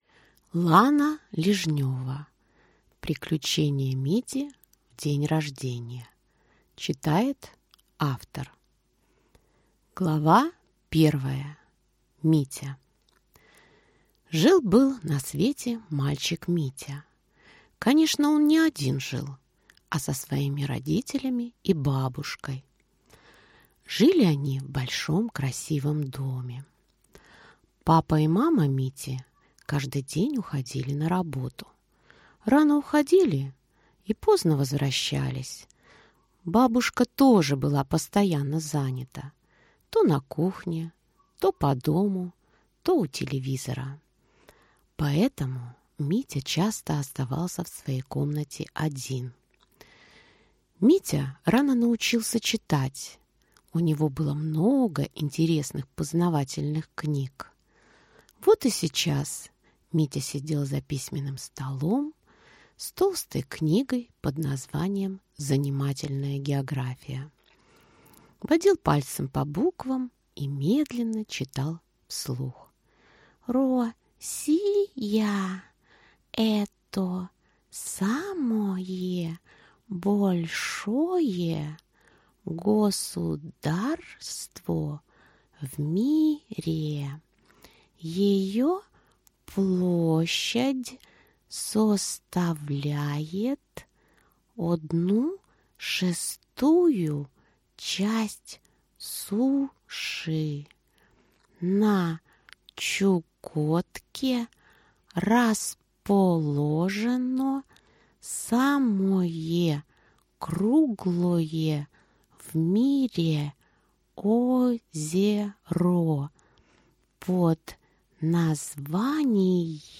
Аудиокнига Приключения Мити в день рождения | Библиотека аудиокниг